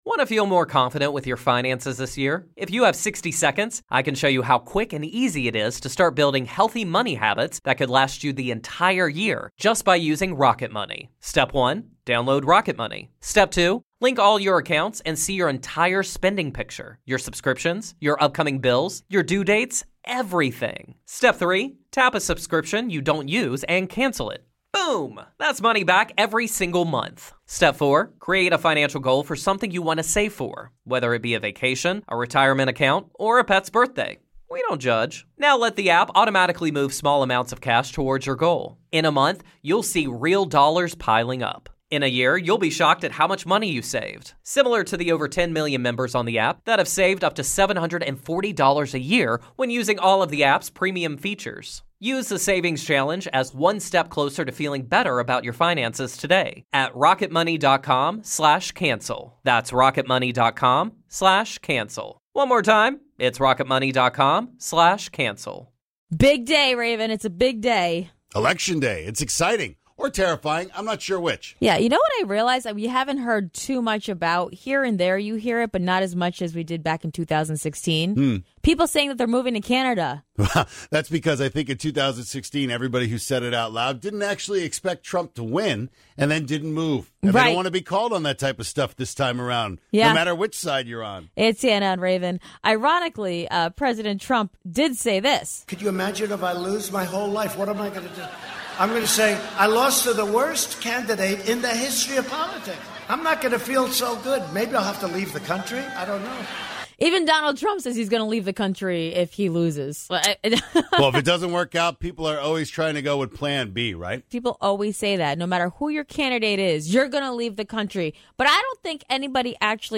Headliner Embed Embed code See more options Share Facebook X Subscribe How many times have you heard that someone will "move to Canada" if their candidate doesn't win? Well, you can call 1800-O-Canada and ask them anything (for real) so we thought we'd call and ask about moving there and if any of those celebrities actually did move in 2016.